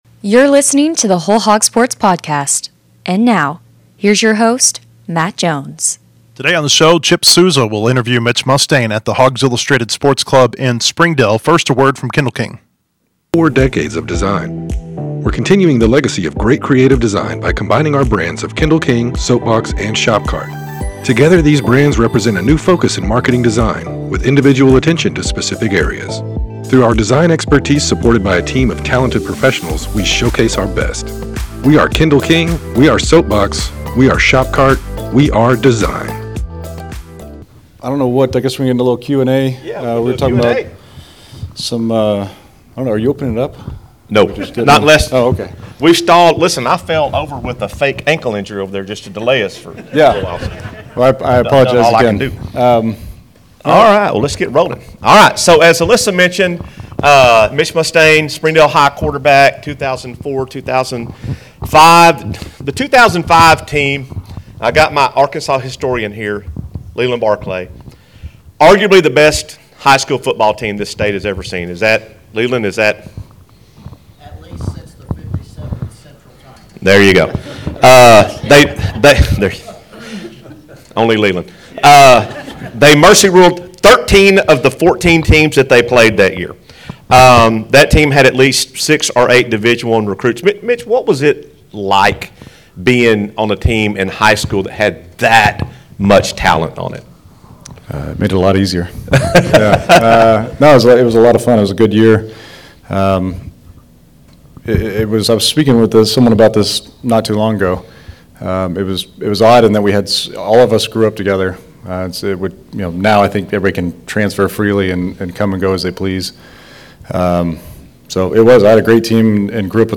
Q&A